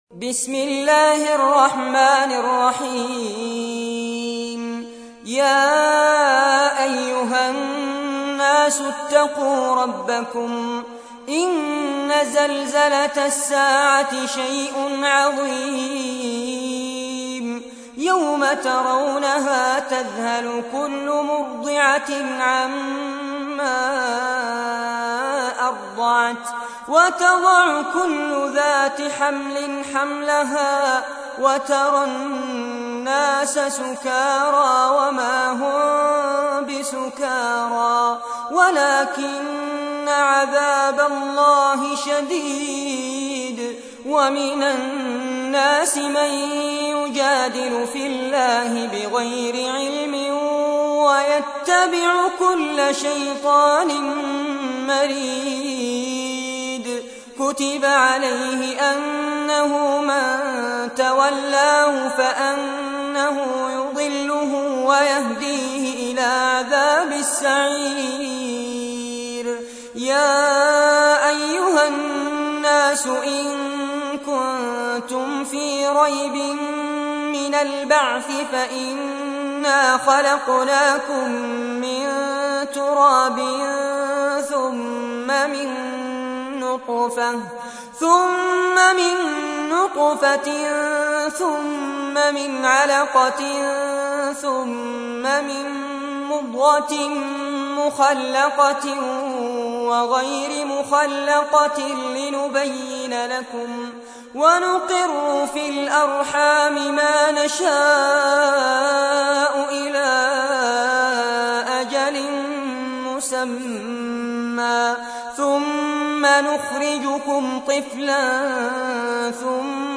تحميل : 22. سورة الحج / القارئ فارس عباد / القرآن الكريم / موقع يا حسين